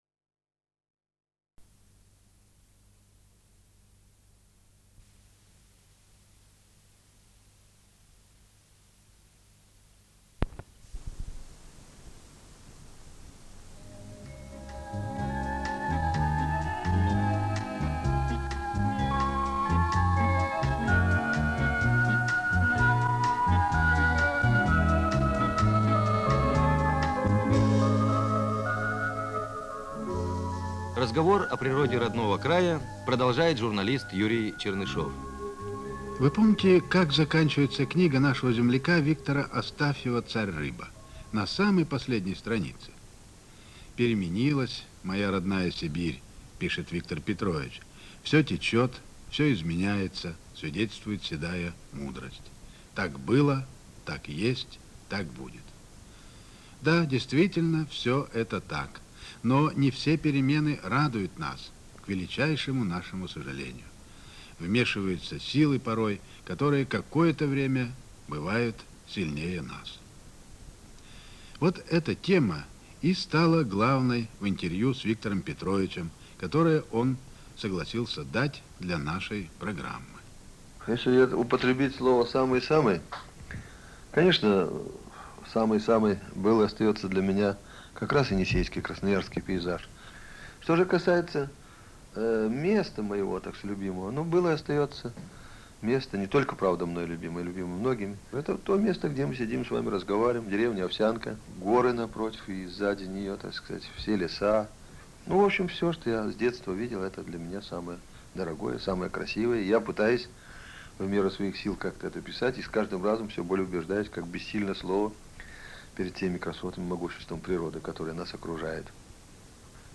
Голос Астафьева.